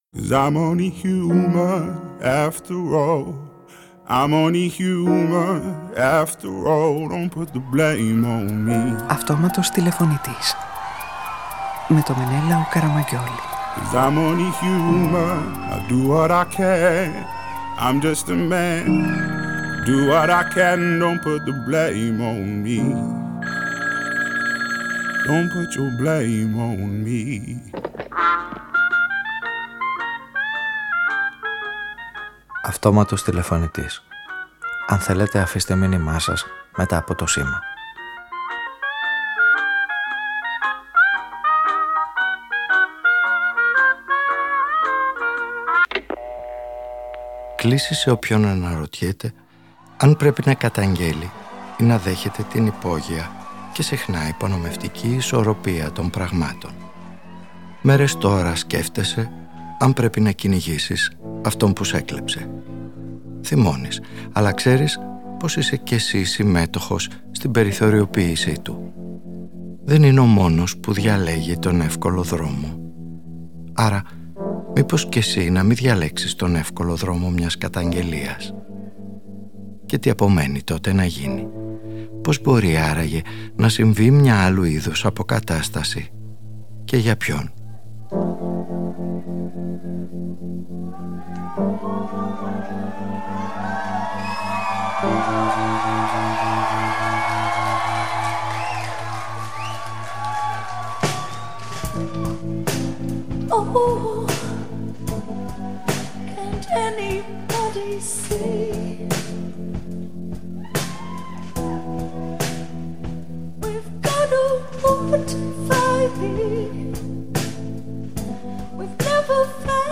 Τι θα είναι πιο αποτελεσματικό; Μια σύλληψη ή μια πιο δημιουργική προσέγγιση του δράστη; Κι αν είναι, ποιά είναι και πώς επιτυγχάνεται; Η σημερινή ραδιοφωνική ιστορία παρακολουθεί τη διαδρομή αυτή και ψάχνει τις απαντήσεις γνωρίζοντας πως στον πόλεμο η νίκη μπορεί να είναι εξίσου πικρή με την ήττα.